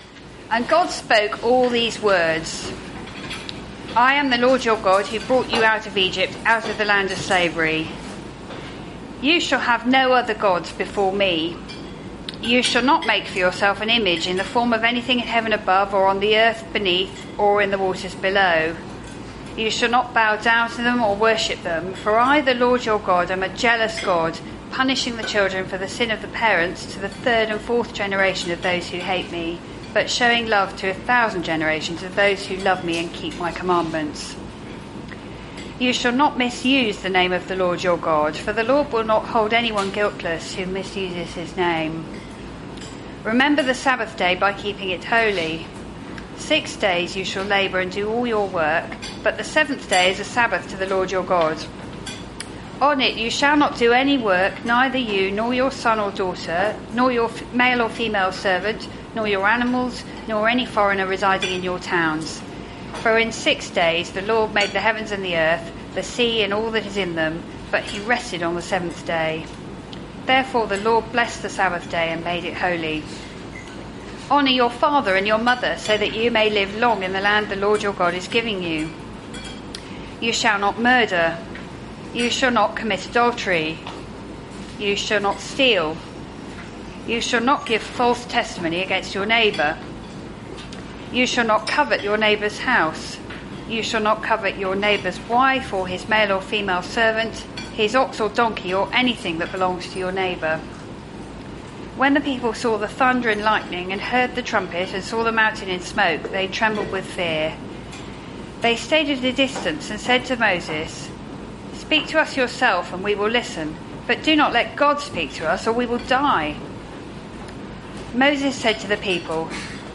given at a Wednesday meeting